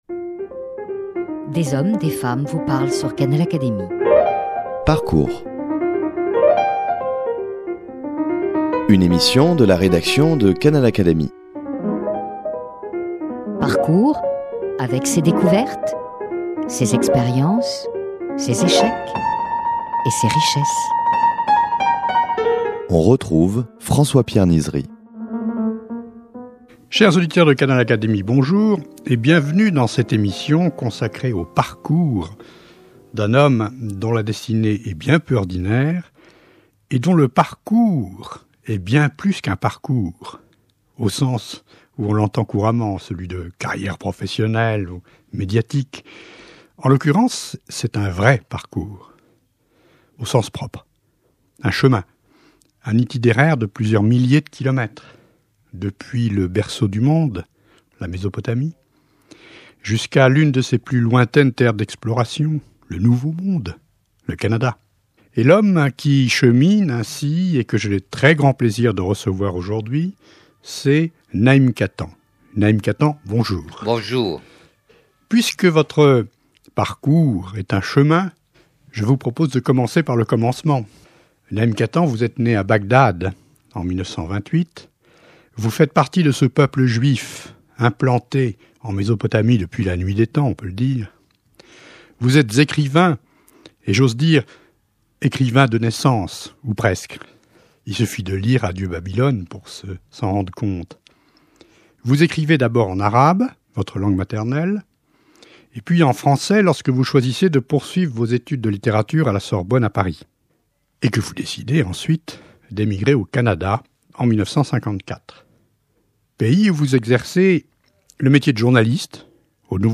Emigré au Canada, il compte aujourd’hui parmi les auteurs majeurs de la littérature québécoise et parmi les meilleurs défenseurs de la langue française. Ecoutez-le parler de ses trois villes de naissance, de l’exil et de la mémoire, des Juifs et des Kurdes, de la langue arabe et du français.